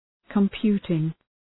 Προφορά
{kəm’pju:tıŋ}